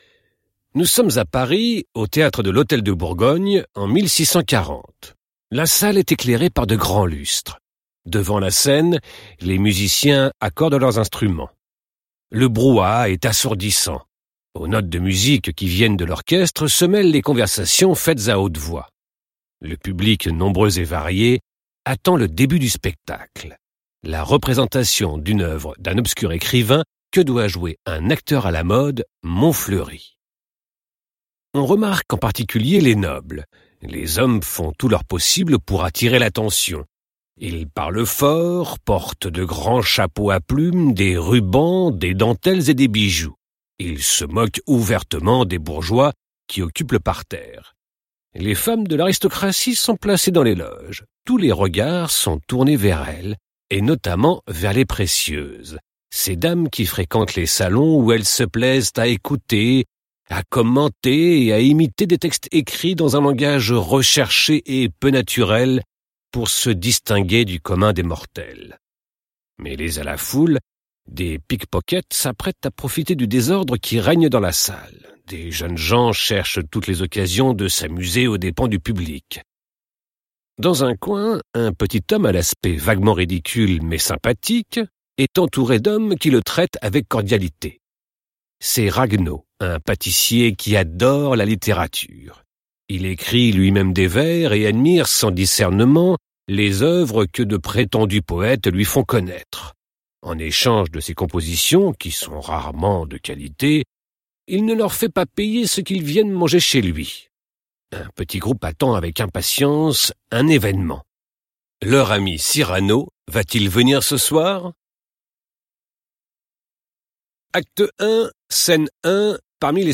Cyrano de Bergerac (FR) audiokniha
Ukázka z knihy